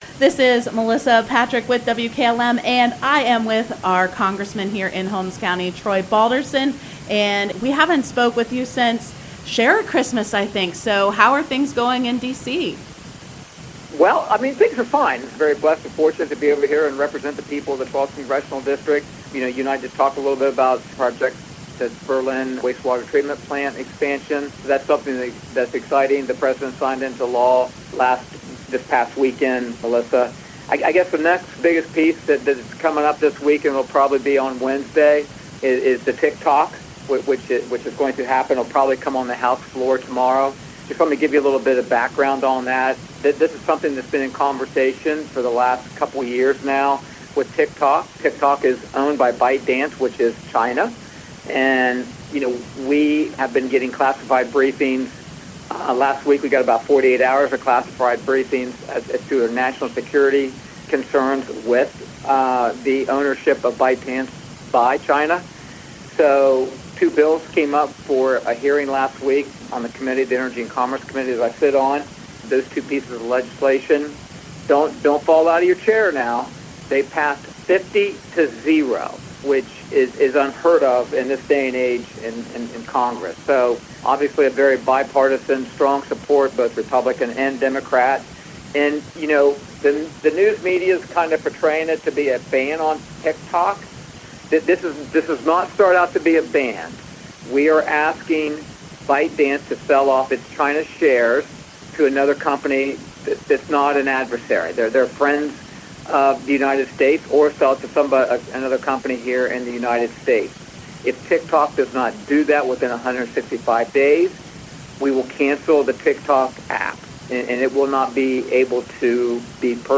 3-13-24 Interview with Congressman Troy Balderson